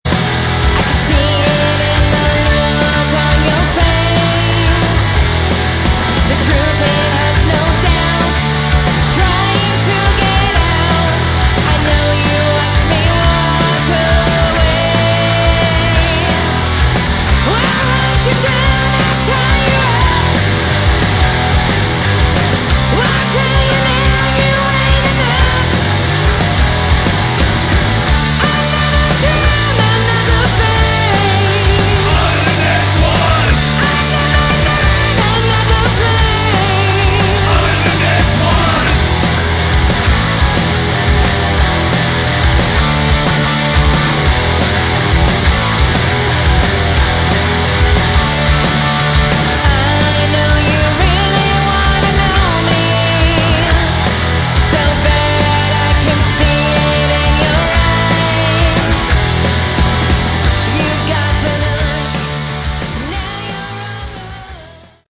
Category: Glam/Punk
vocals
guitar, backing vocals, piano
bass, backing vocals
drums, backing vocals